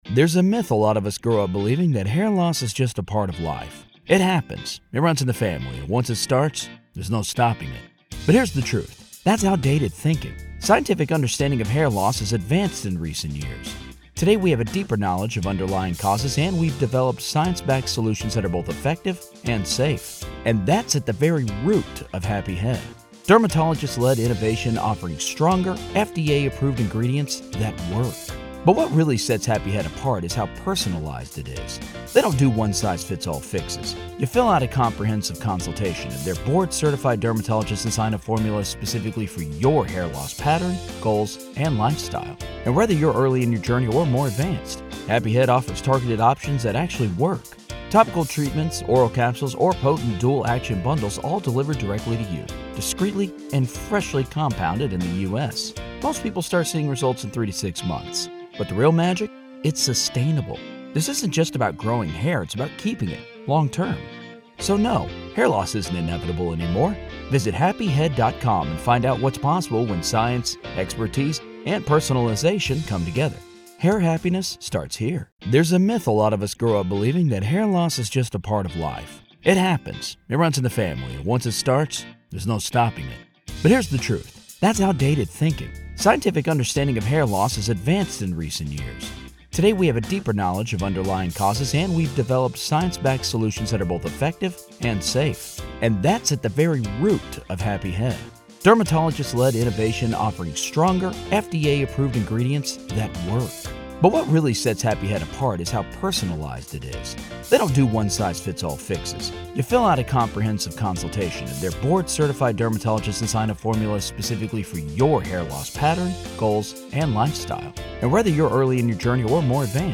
COMMONWEALTH CLOSING ARGUMENTS PART 1: The Trial of Karen Read: Boyfriend Cop Murder Trial – MA v. Karen Read
Welcome to a special episode of "The Trial of Karen Read," where today, we find ourselves inside the courtroom of the case against Karen Read.